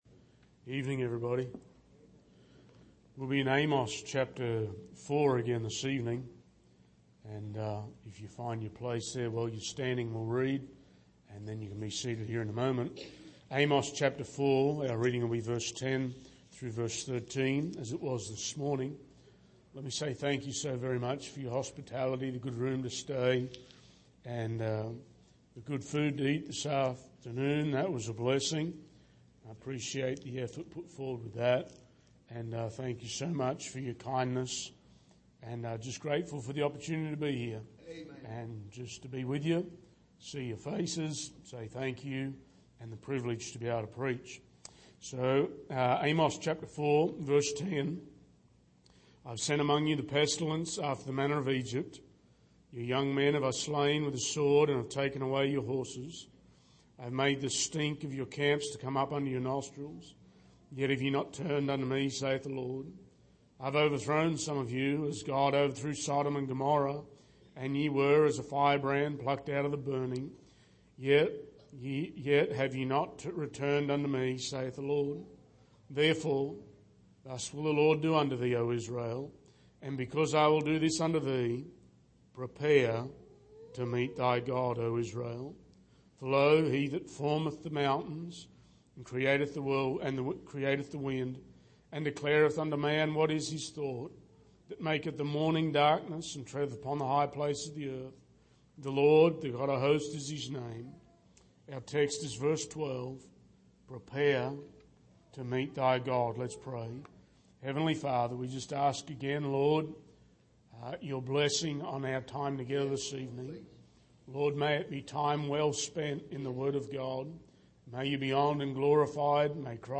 Amos 4:10-13 Service: Sunday Evening Saint